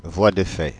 Ääntäminen
voie de fait France (Île-de-France)